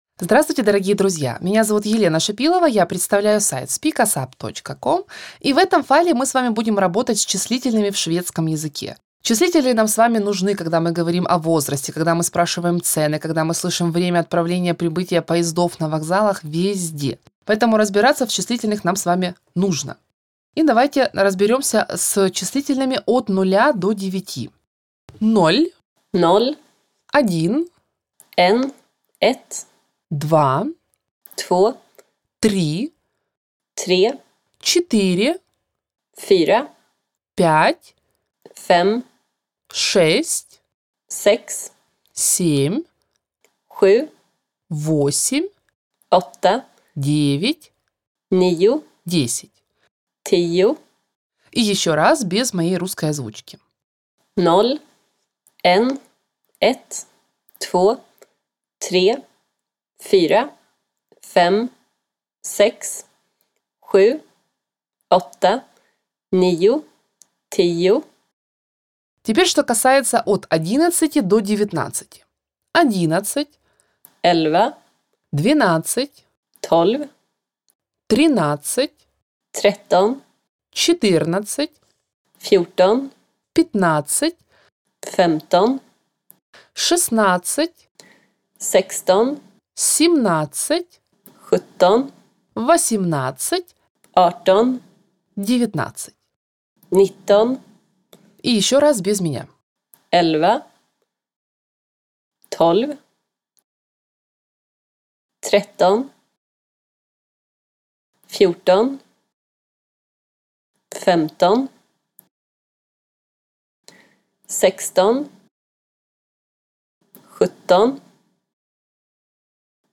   Прослушайте аудио урок с дополнительными объяснениями